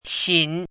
chinese-voice - 汉字语音库
xin3.mp3